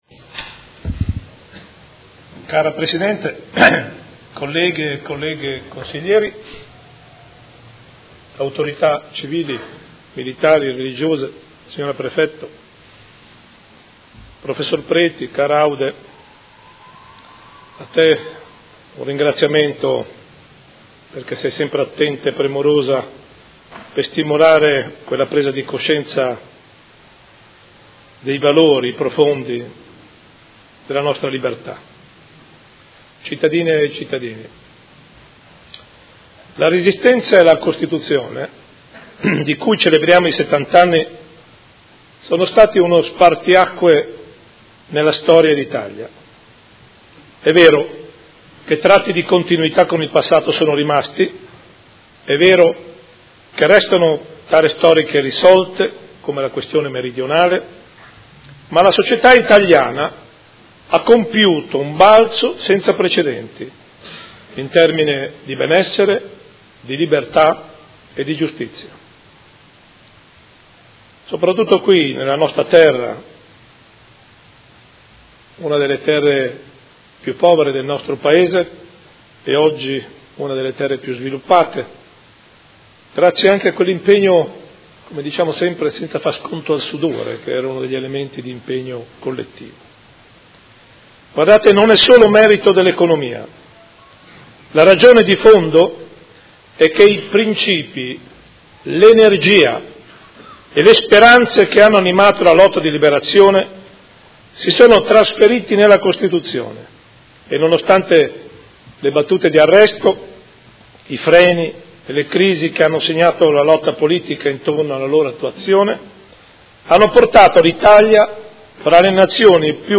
Sindaco — Sito Audio Consiglio Comunale
Seduta del 19/04/2018. Commemorazione della Liberazione di Modena